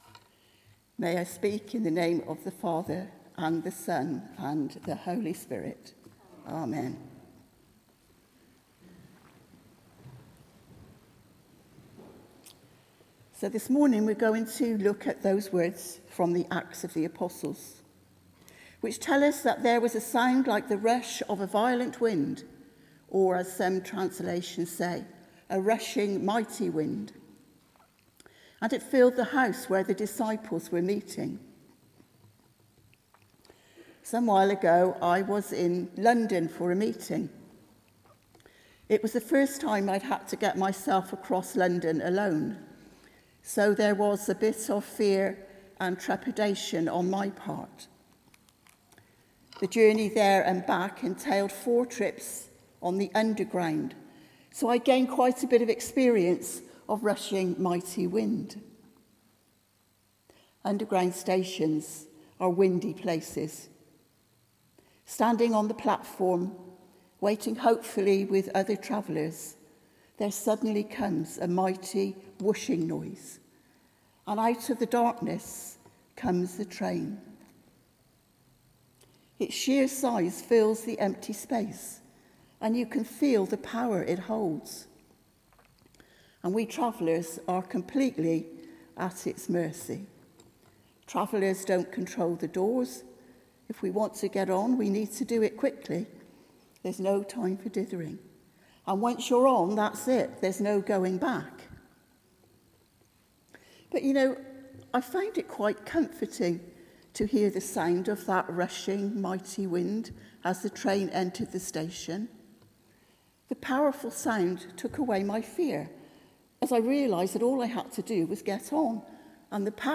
Sermon: Receiving and Inclusion | St Paul + St Stephen Gloucester